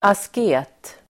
Ladda ner uttalet
Uttal: [ask'e:t]